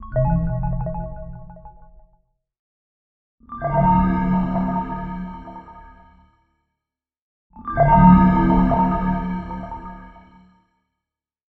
Notification (Strange Pulse)
alarm alert alert-sound cell-phone computer-error-sound email-alert email-notification error sound effect free sound royalty free Memes